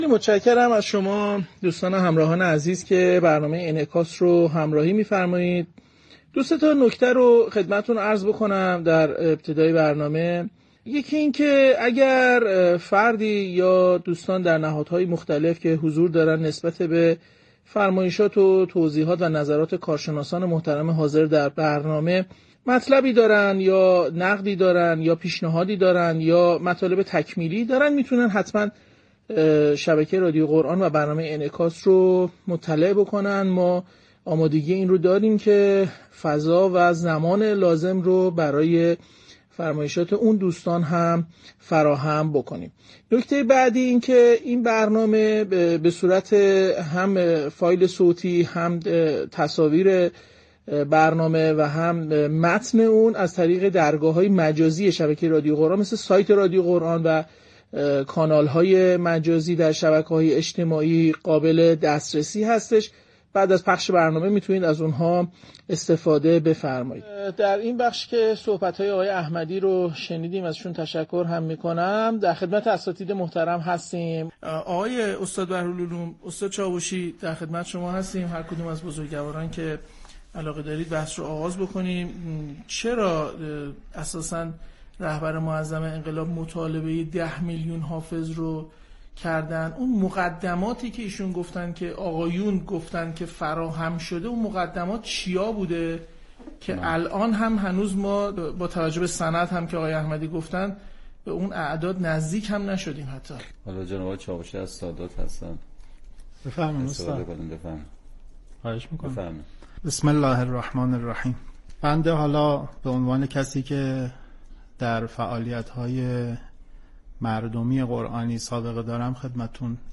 سومین قسمت از برنامه «انعکاس» با موضوع بررسی چگونگی و ارائه راه‌های فراگیر شدن حفظ قرآن در کشور از شبکه رادیویی قرآن پخش شد.